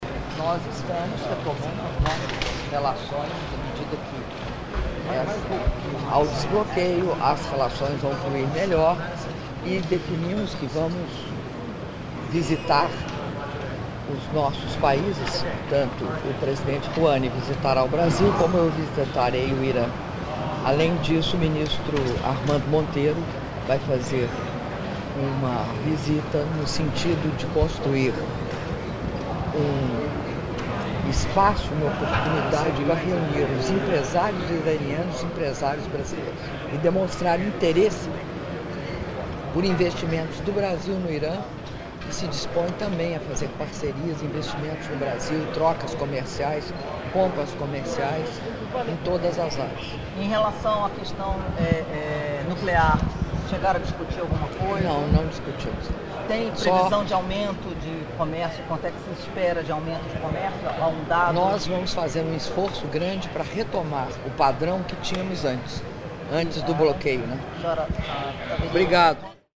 Áudio da entrevista coletiva concedida pela Presidenta da República, Dilma Rousseff, após encontro com o presidente da República Islâmica do Irã, Hassan Rohani - Nova Iorque/EUA (01min09s) — Biblioteca